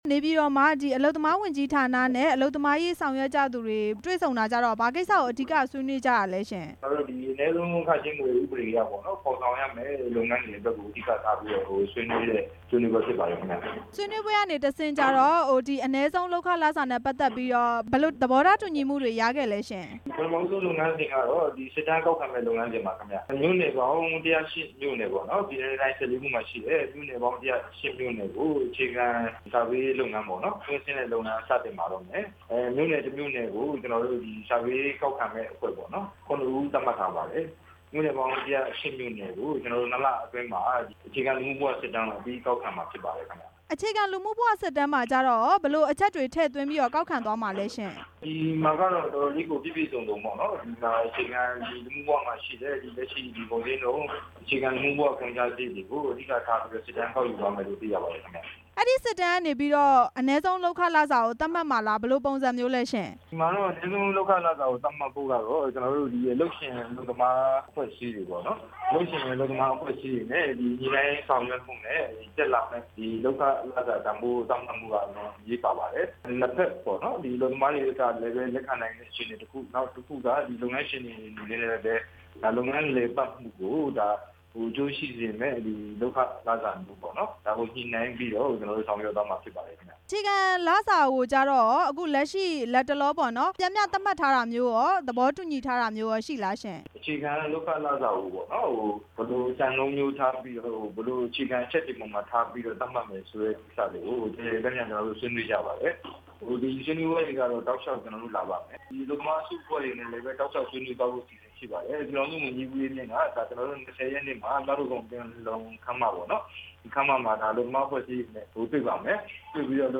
အနည်းဆုံးလုပ်ခလစာ သတ်မှတ်နိုင်ရေး ဆွေးနွေးပွဲ အကြောင်း မေးမြန်းချက်